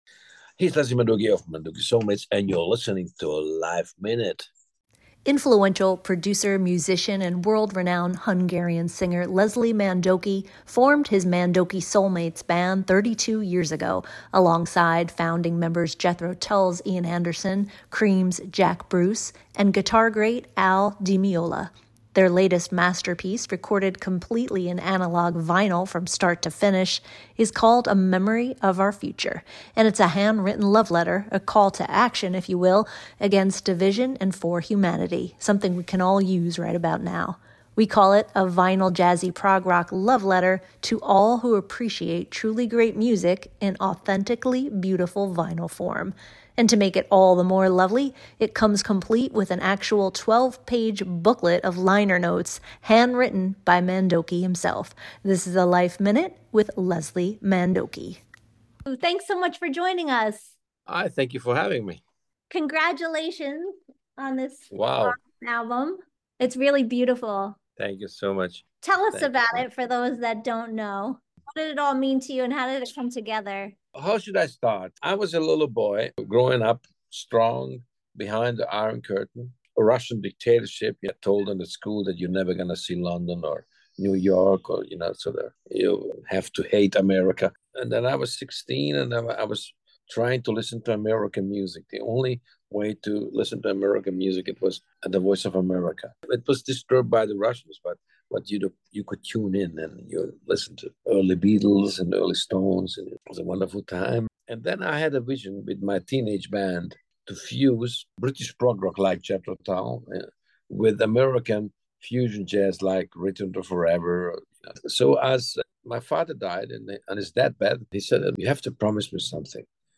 I sat down with him virtually to hear all about it. This is a LifeMinute with Leslie Mandoki.